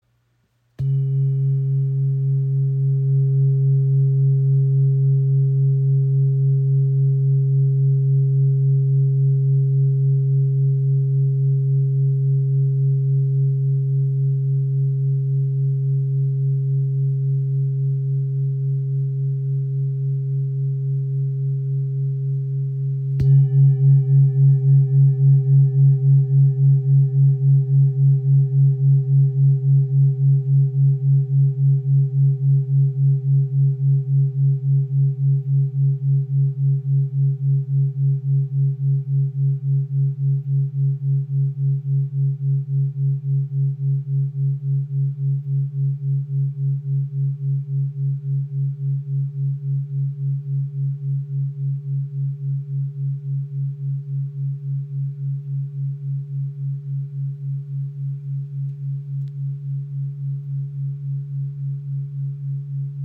Klangschale mit Shri Yantra | ø 23.6 cm | Ton ~ C# | OM-Ton / Erdtons (136,10 Hz)
Handgefertigte Klangschale aus Kathmandu
• Icon Inklusive passendem rotem Filzschlägel
Ihr obertonreicher Klang im Ton C#  ist klar und zentrierend.